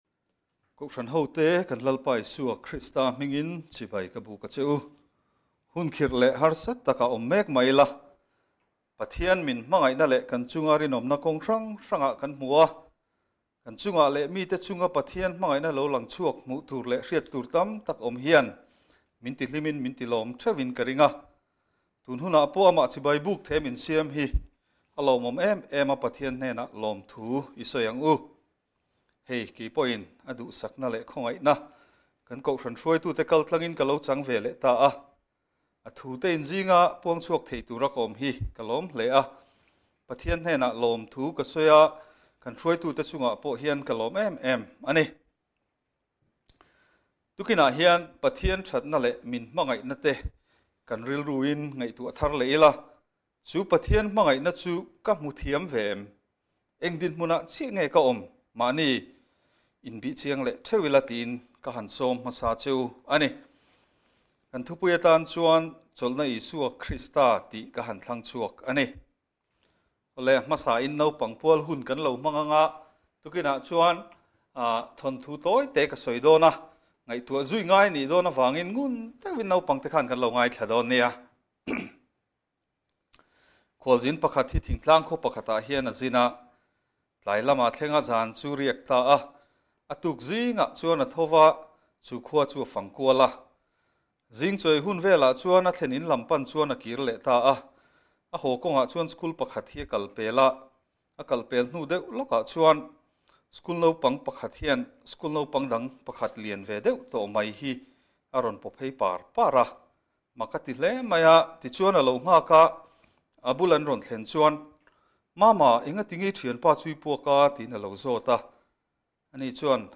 THUCHAH